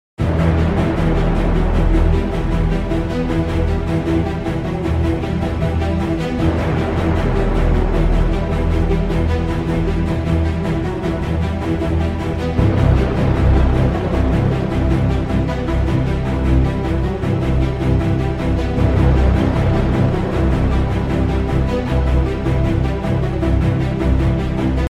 “Fate” in the sketchbook. Ostinato